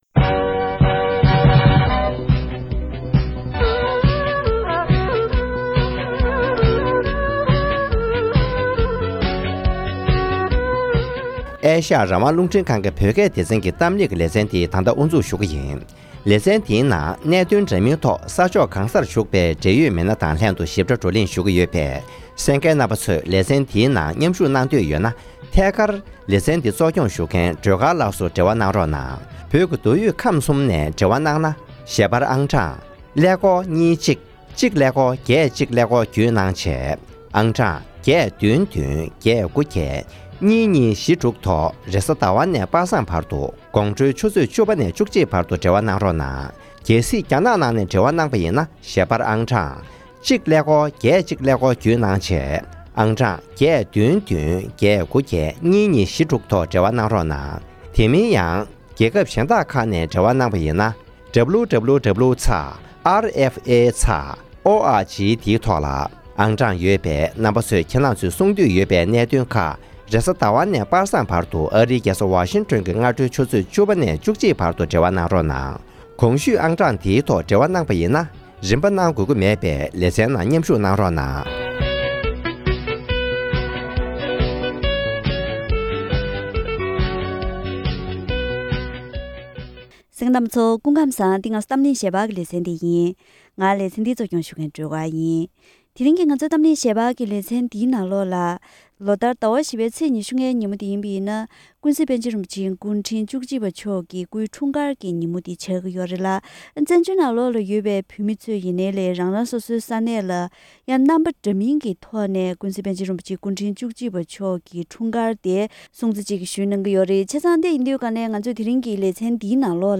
༄༅། །ཐེངས་འདིའི་གཏམ་གླེང་ལེ་ཚན་འདིའི་ནང་།